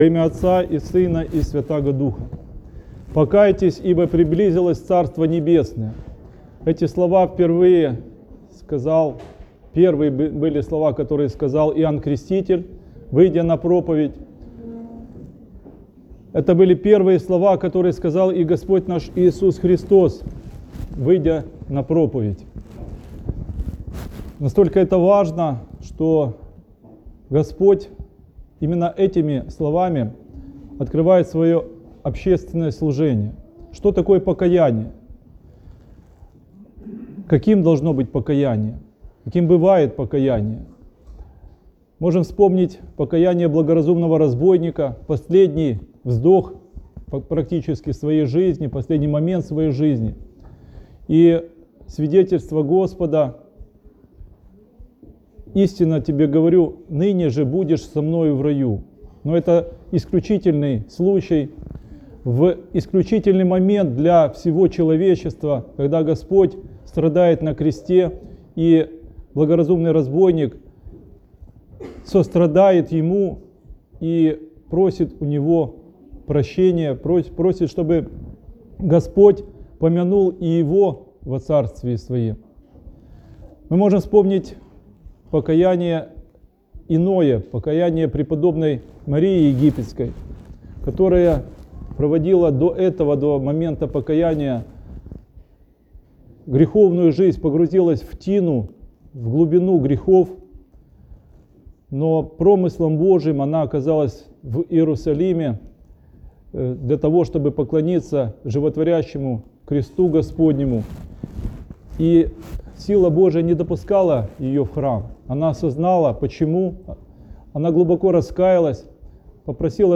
Проповедь. Неделя по Богоявлении. О покаянии (+АУДИО)